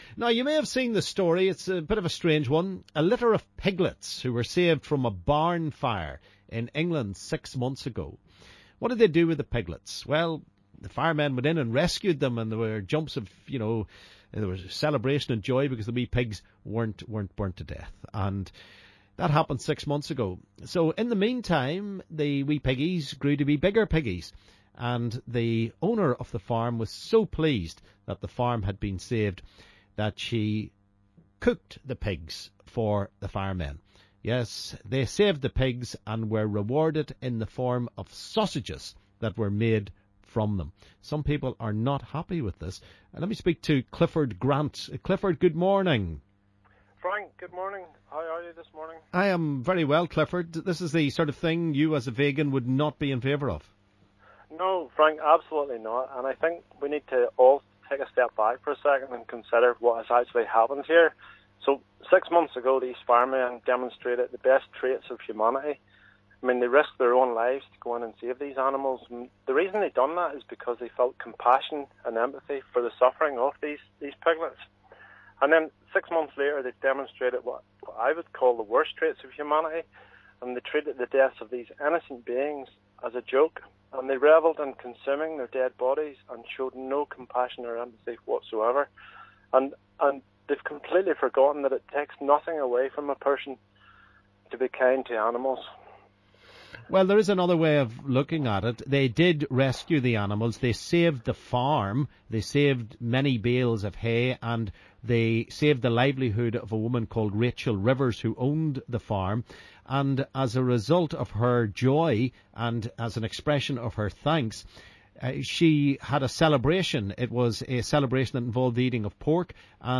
LISTEN ¦ Debate - should we eat meat?